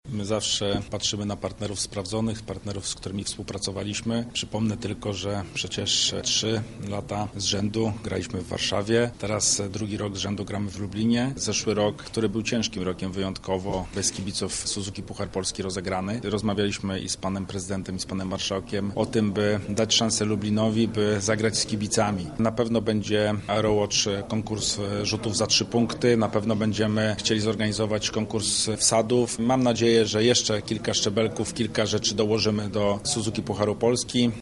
• mówi prezes Polskiego Związku Koszykówki Radosław Piesiewicz.